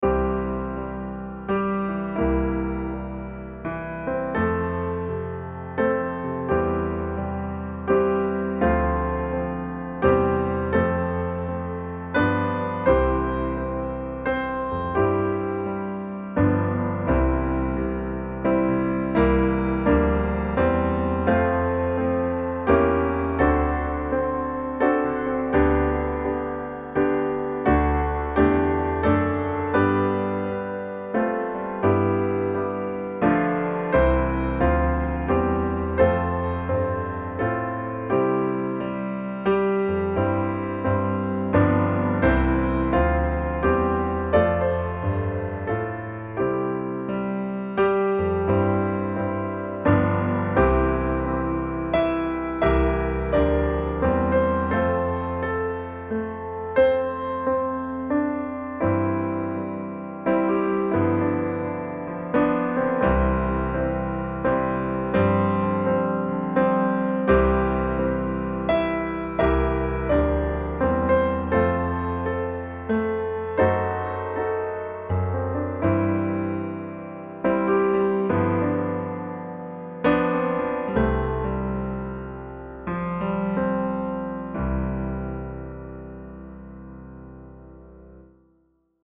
piano music
Here’s a traditional gospel tune by Samuel Webbe, words by Thomas Moore and Thomas Hastings.